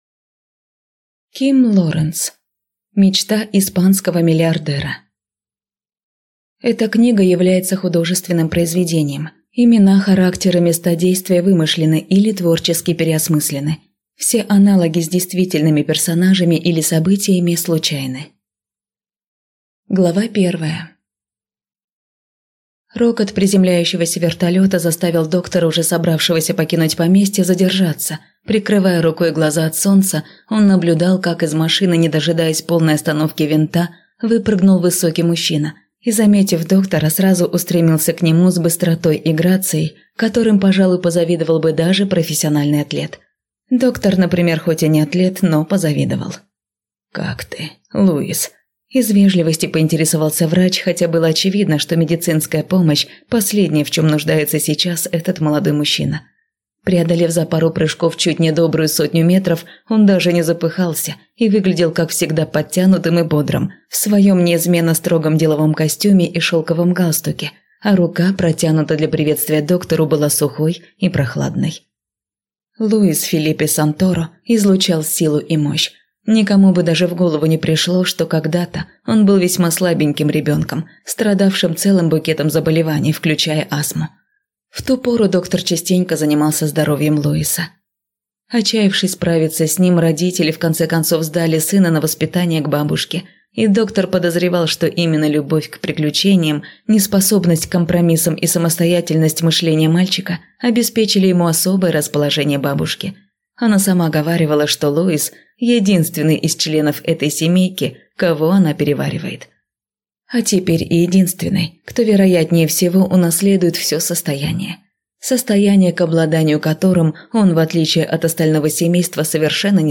Аудиокнига Мечта испанского миллиардера | Библиотека аудиокниг